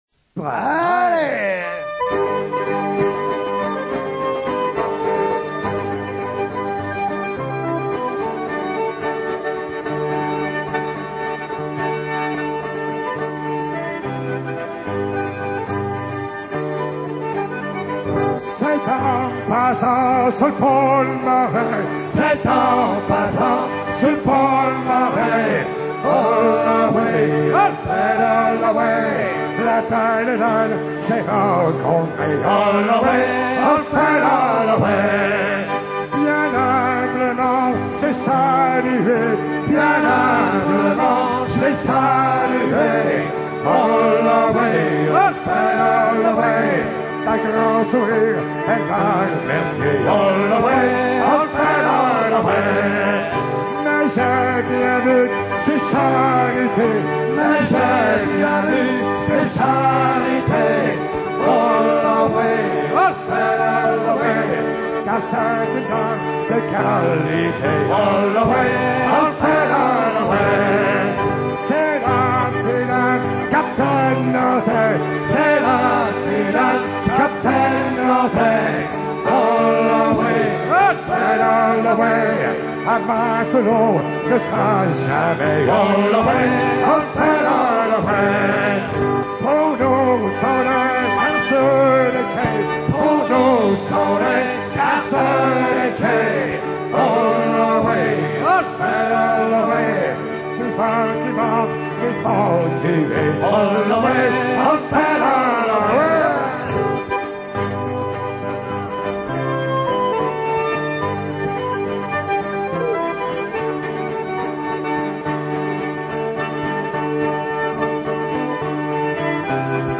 gestuel : à hisser main sur main
circonstance : maritimes
Pièce musicale éditée